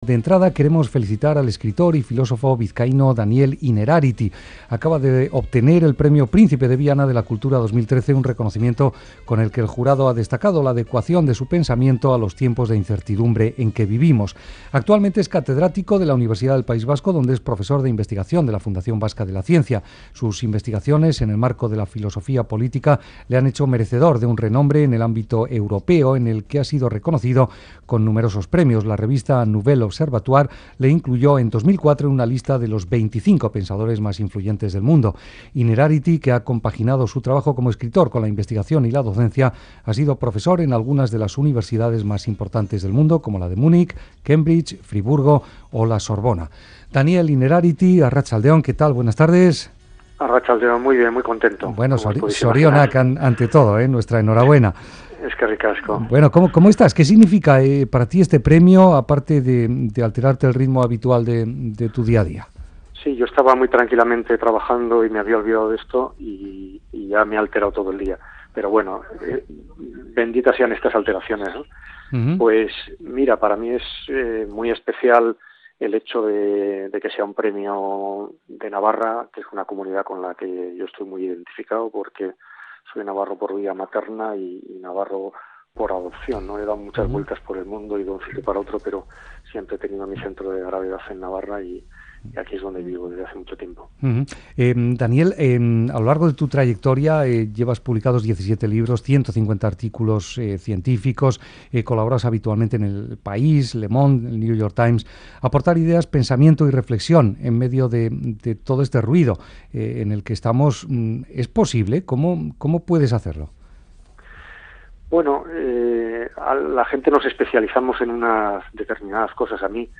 Hablamos con Daniel Innerarity, nuevo Premio Príncipe de Viana